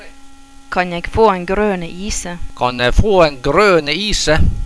Da andre so gjerne forsvinde ganske fort, e den likkje  ”e” lyden